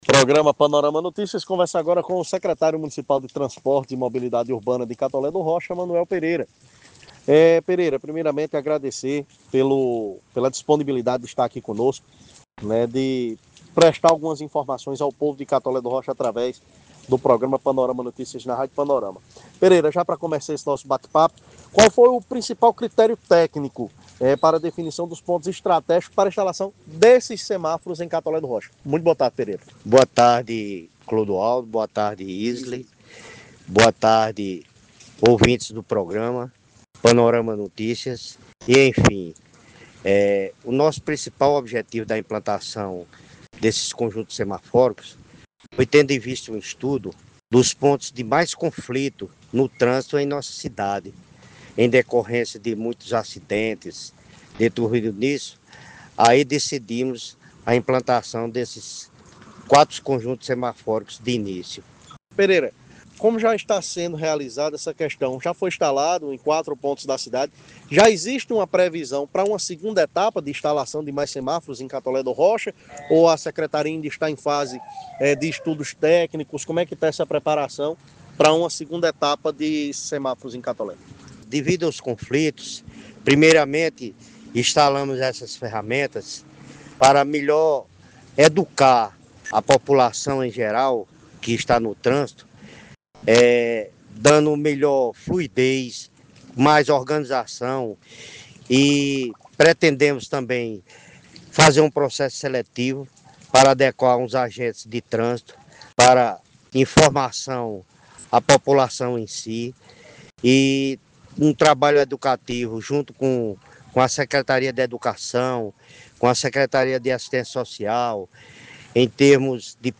Ouça a entrevista com o secretário de Transportes e Mobilidade Urbana, Manoel Pereira: